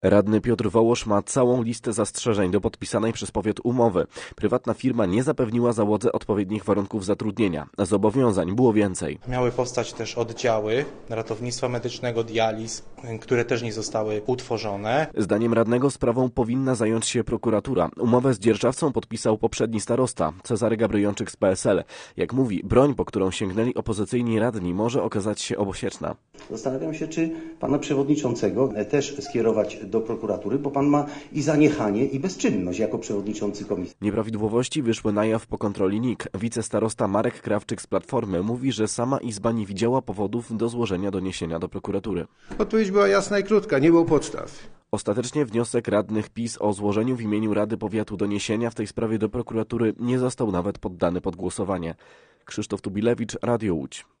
Posłuchaj relacji: Nazwa Plik Autor Burzliwa sesja w sprawie łaskiego szpitala audio (m4a) audio (oga) Warto przeczytać Fly Fest 2025.